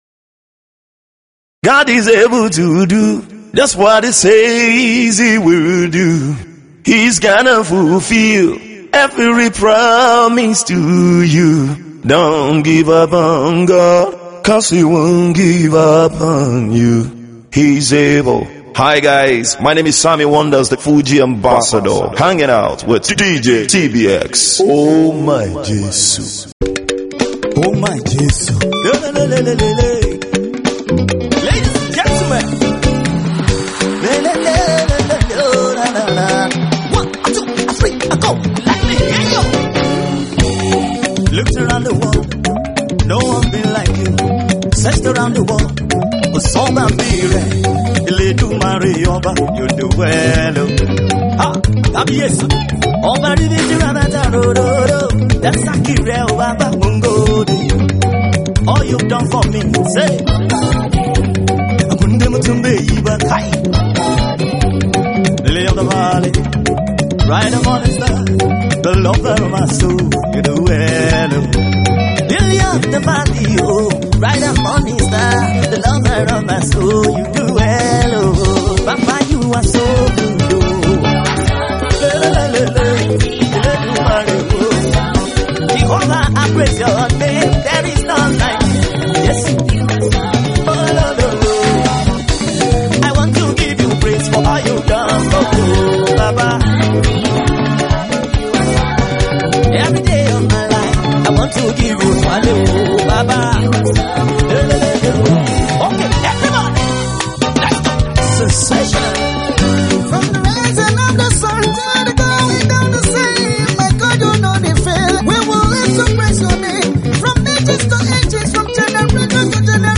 fuji music
just dance vibes